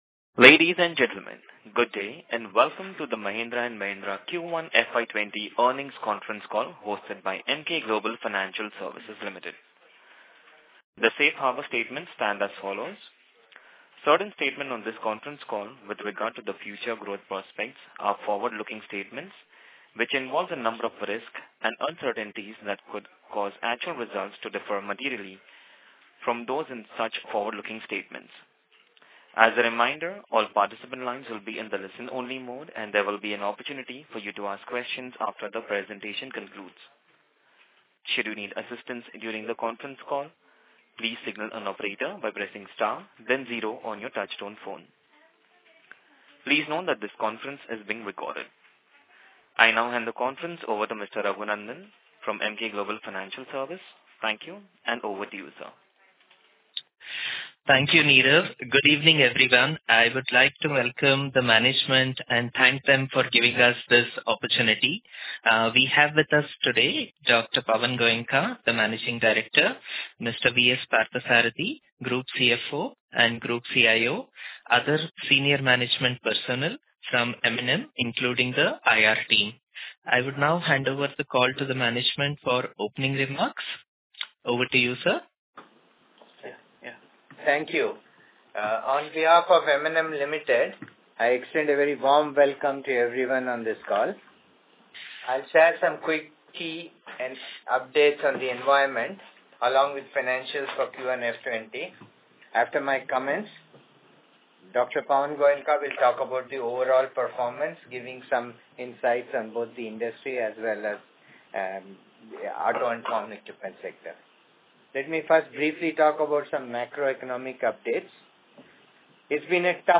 AUDIO – M&M Q1FY20 Earnings Con-call – 7th Aug 2019